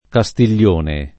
kaStil’l’1ne] s. m. — voce ant. per «castello», sopravvivente come top. o elem. di toponimi, spesso con tronc.: Castiglione (o Castiglion) del Lago (Umbria), Castiglione (o Castiglion) dei Pepoli (E.-R.), Castiglione (o Castiglion) della Pescaia (Tosc.); anche in altri casi come questi, spec. davanti alla prep. di, con alternanza tra un uso parlato con tronc. e un uso uffic. senza — sempre senza tronc. davanti a voc., per es. in Castiglione a Casauria (Abr.), Castiglione Olona (Lomb.), Castiglione in Teverina (Lazio); e anche in altri casi, non solo nell’uso uffic.: Castiglione del Genovesi (Camp.), Castiglione Messer Marino (Abr.), Castiglione delle Stiviere (Lomb.) — sempre con tronc., anche nell’uso uffic., in Castiglion Fibocchi, Castiglion Fiorentino, Castiglion Fosco, nonché in Castiglion che Dio nol sa — come top. senz’aggiunte, ora Castiglione ora Castiglioni, pur indicando un unico castello o centro abitato con la forma apparentem. plurale in -ni: forma regolarm. ricorrente in molti usi parlati locali come equivalente alla forma uffic. in -ne quando non le siano fatte seguire le eventuali aggiunte (es. Castiglione della Pescaia o C. dei Pepoli, localm. Castiglioni e basta, l’uno e l’altro) — sim. i cogn. Castiglion [kaStil’l’1n], Castiglione, Castiglioni